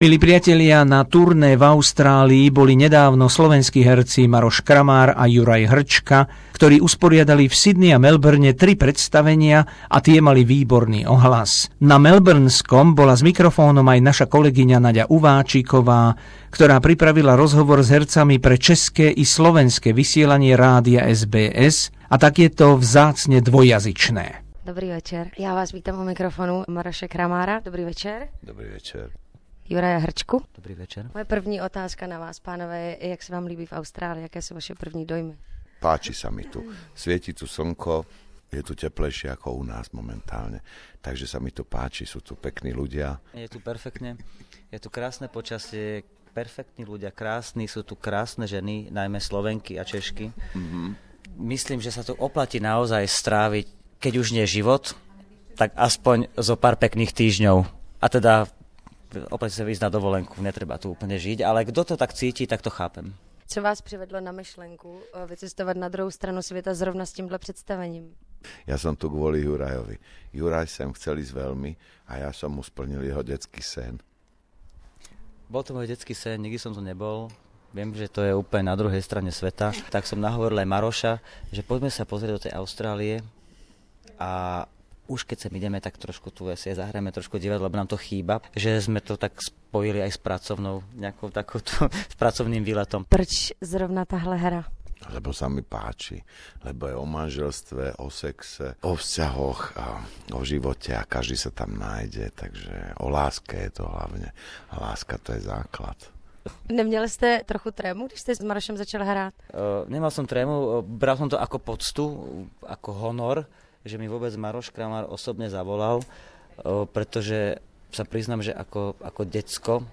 Interview with the famous Slovak actors Maros Kramar and Juraj Hrcka after the theatre performances in Sydney and Melbourne.